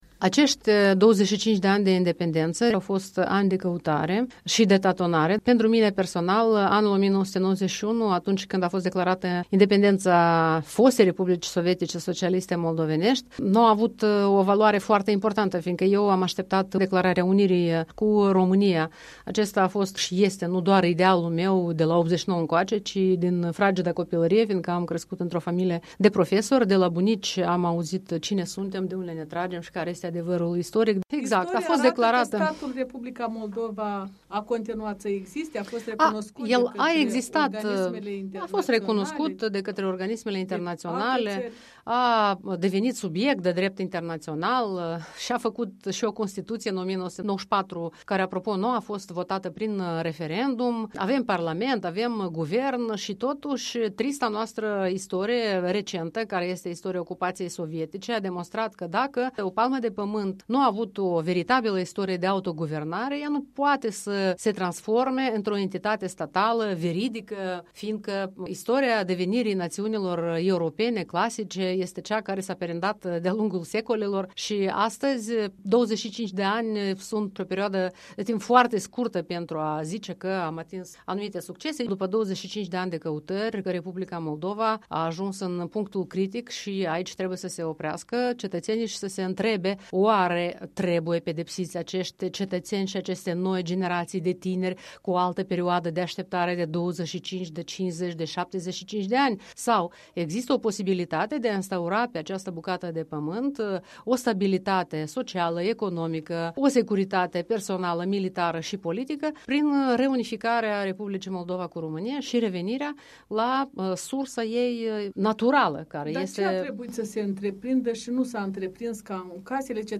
Interviu cu Ana Guțu